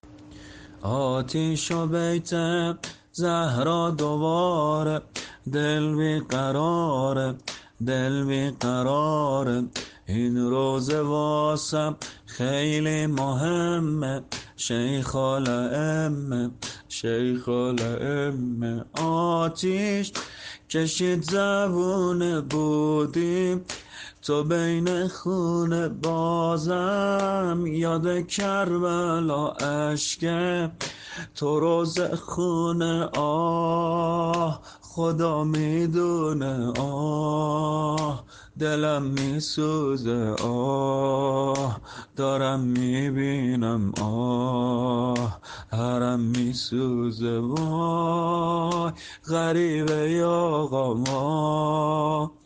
زمینه شهادت امام صادق علیه السلام